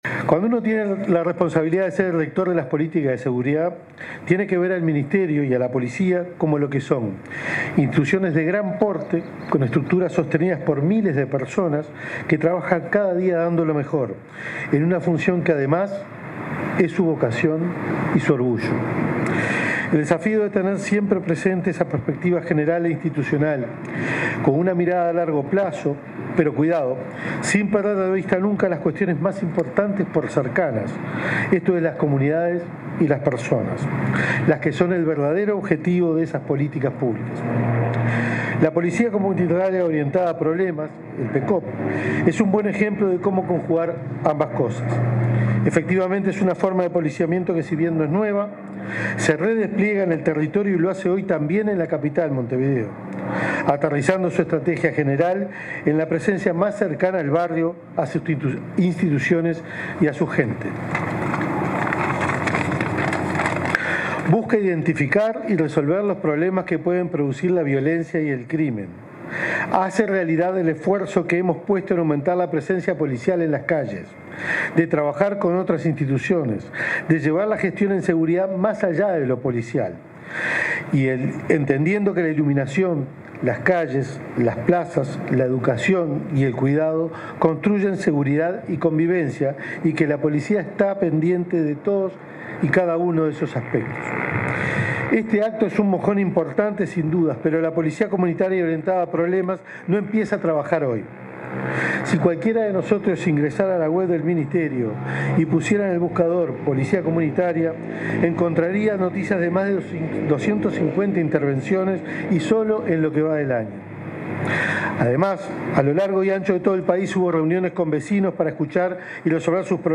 Palabras del ministro del Interior, Carlos Negro